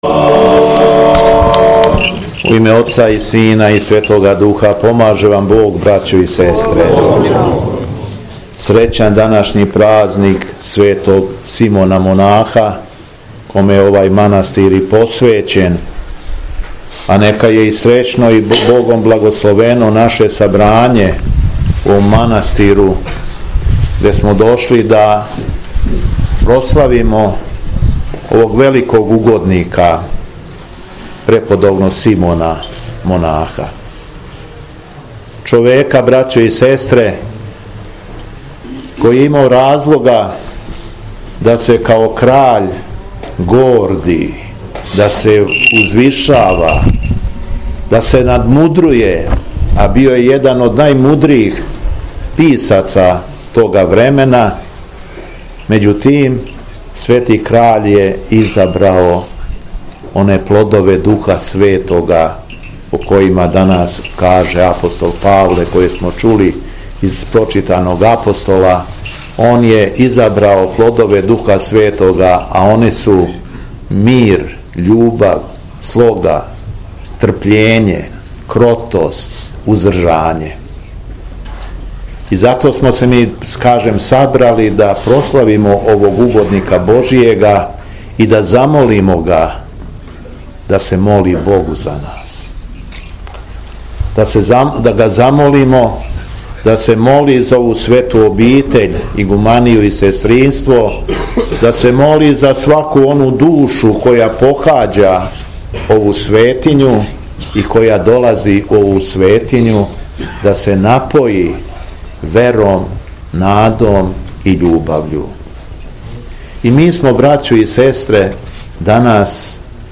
СВЕТА АРХИЈЕРЕЈСКА ЛИТУРГИЈА У МАНАСТИРУ ПРЕРАДОВАЦ
Беседа Његовог Високопреосвештенства Митрополита шумадијског г. Јована